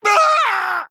• 语音